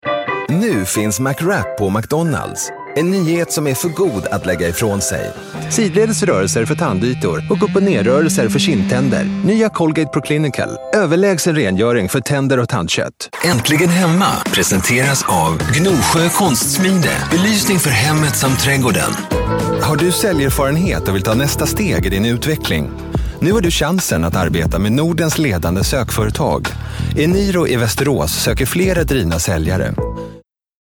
Commercial 1